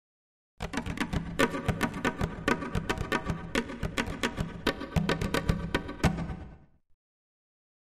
Drums Asian Dance Beat 2 - Short